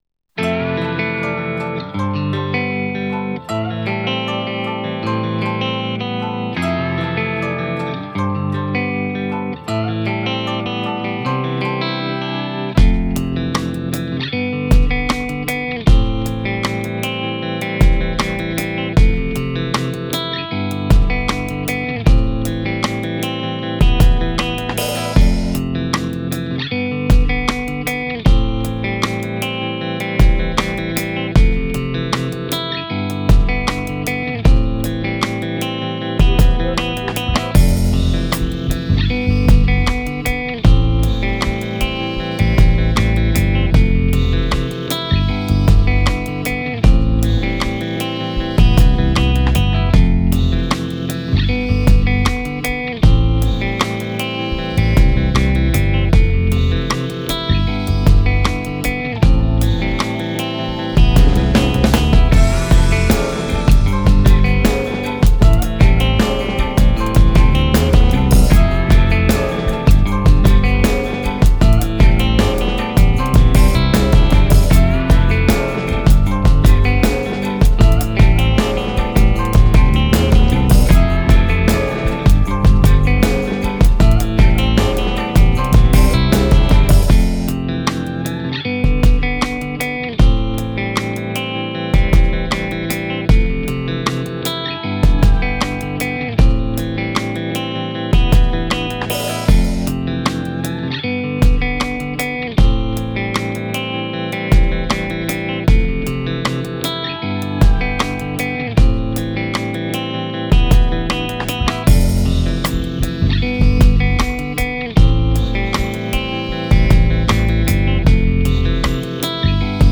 Type Beat